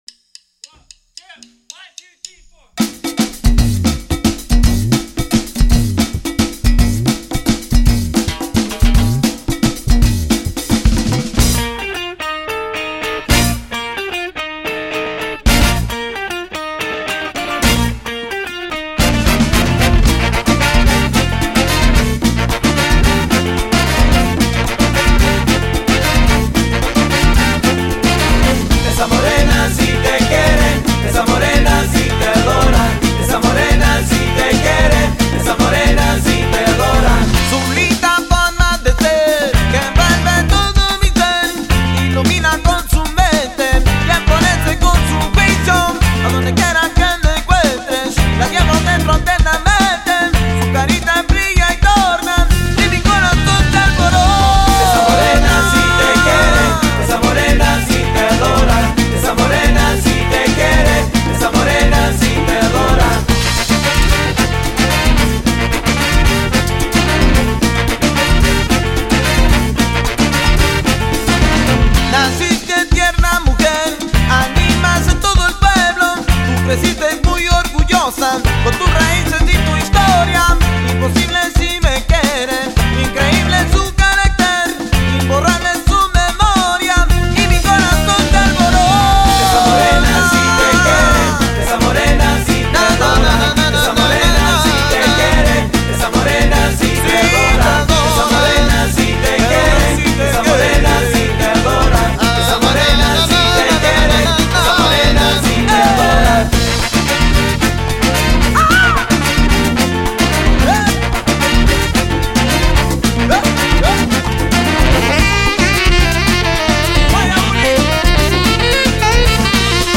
Latin/funk/hip-hop/modern rock outfit